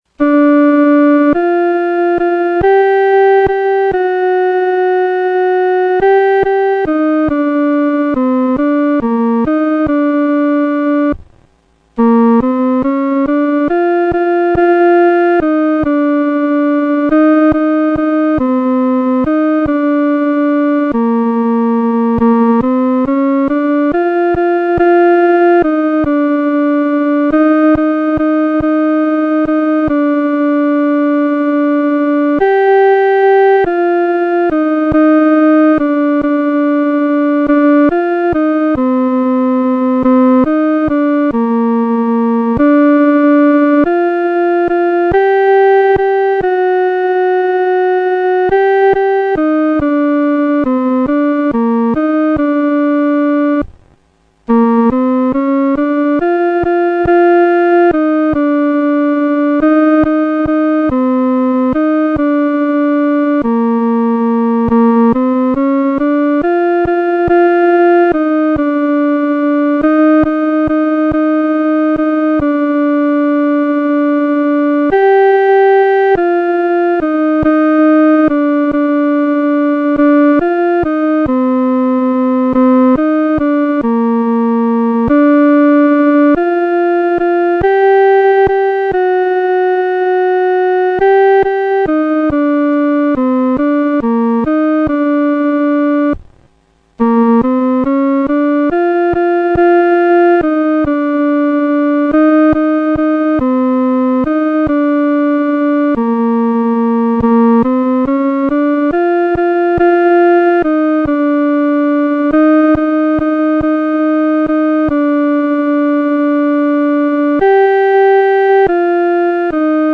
独奏（第二声）
天父必看顾你-独奏（第二声）.mp3